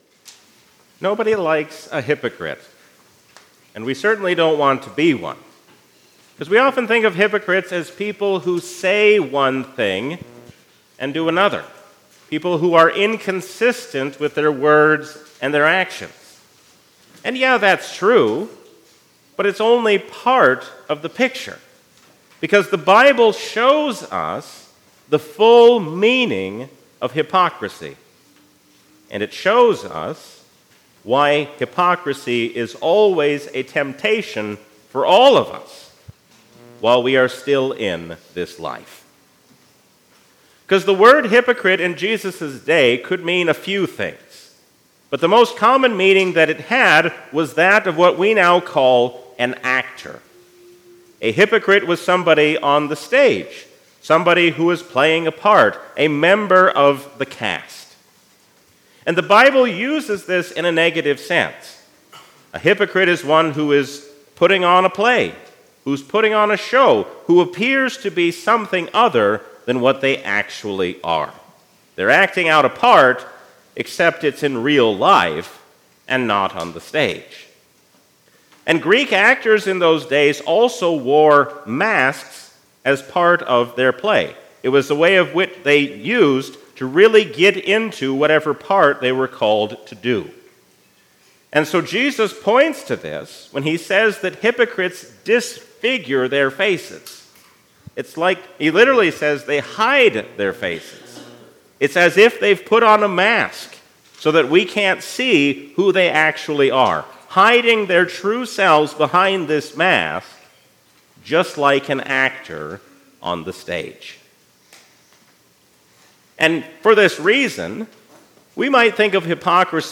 A sermon from the season "Lent 2023." Jesus teaches us what it means to seek after the will of God, even as we pray for things to be taken away.